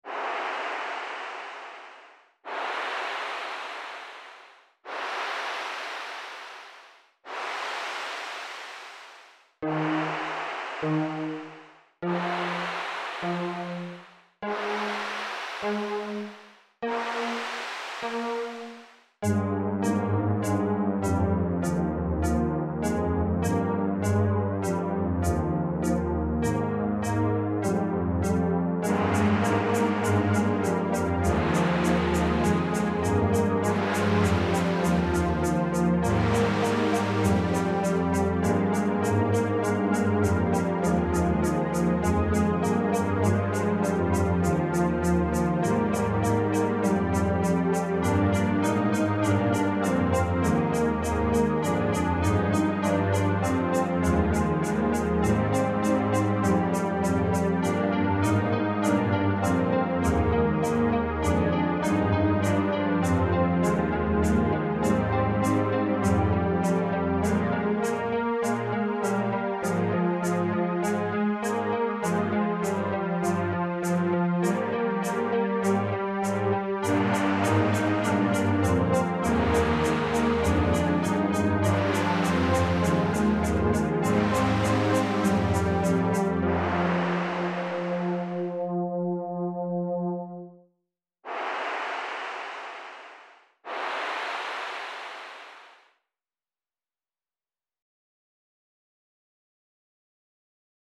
Named after the Great Comet of 1811. It's beautiful, but also terrifying. 27/10/2024